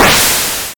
hit.mp3